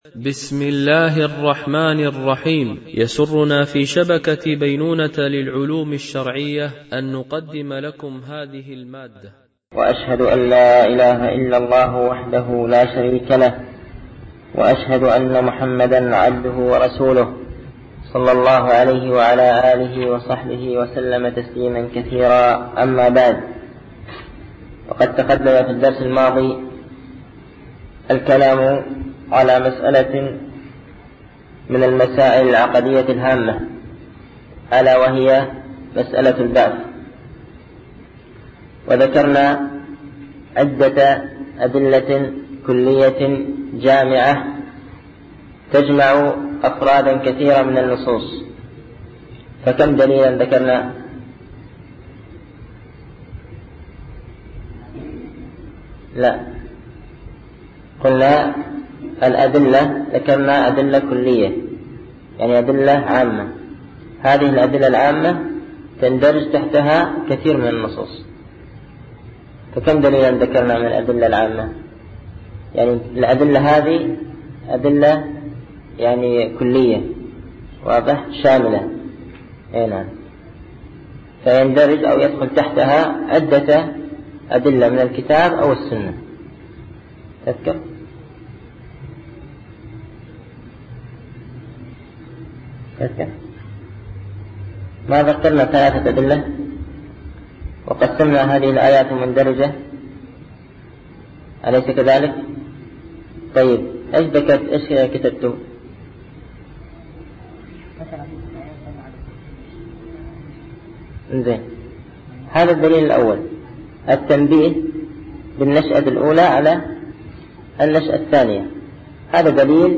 شرح أعلام السنة المنشورة ـ الدرس 41 ( ما دليل النفخ في الصور وكم نفخات ينفخ فيه ؟ )